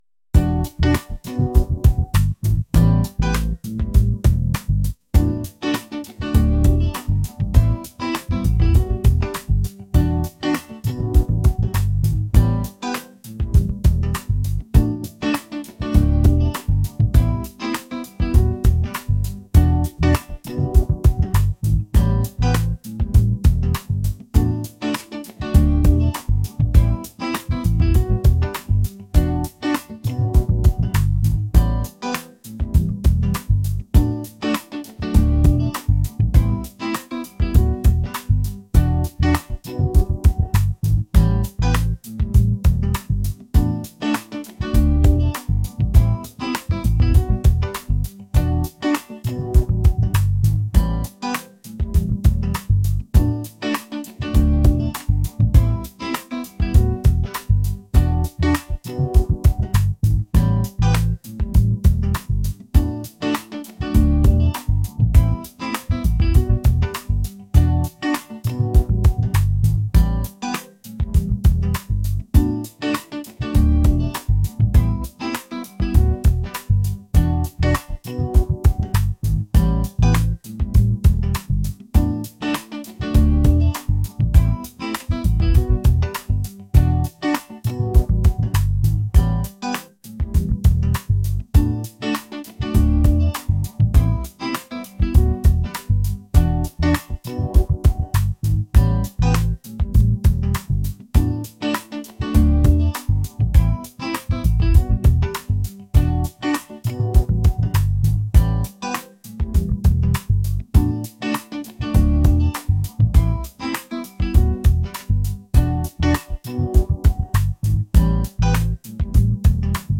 smooth | soul | groovy